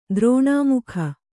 ♪ drōṇāmukha